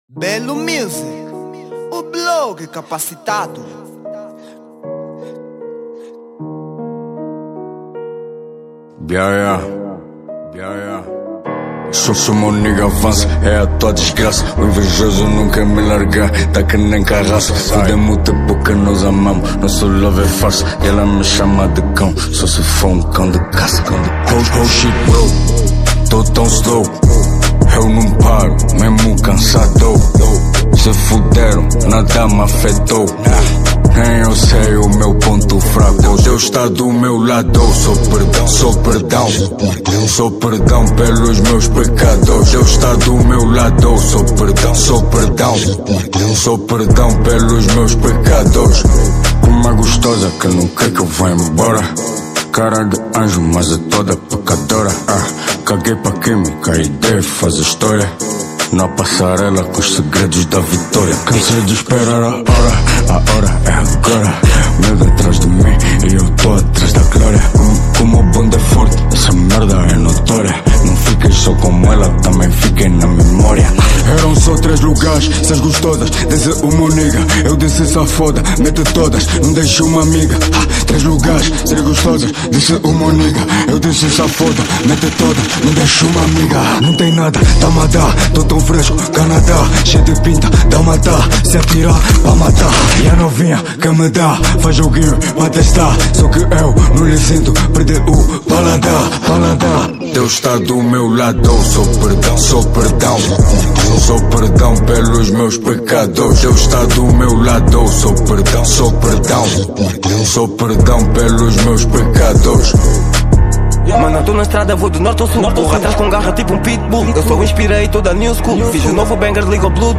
Género : Rap